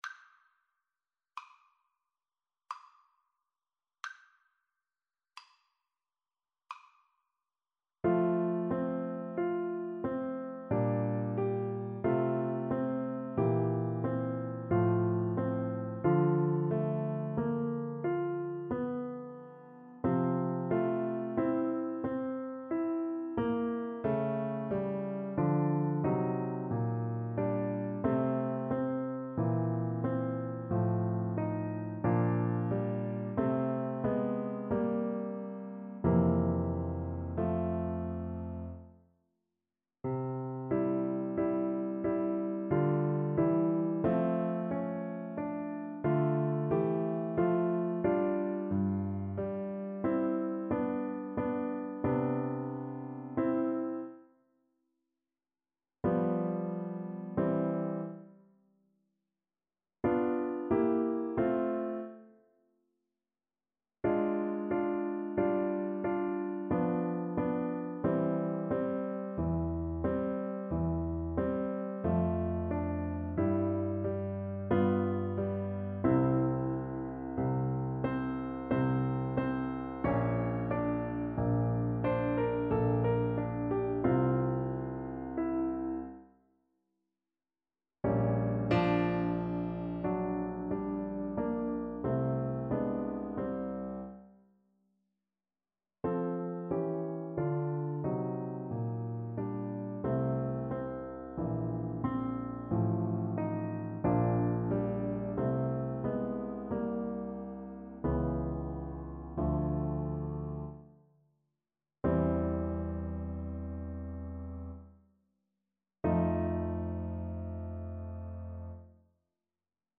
Play (or use space bar on your keyboard) Pause Music Playalong - Piano Accompaniment Playalong Band Accompaniment not yet available reset tempo print settings full screen
F major (Sounding Pitch) C major (French Horn in F) (View more F major Music for French Horn )
3/4 (View more 3/4 Music)
Adagio =45
Classical (View more Classical French Horn Music)